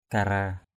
/ka-ra:/ (d.) rùa = tortue. turtle.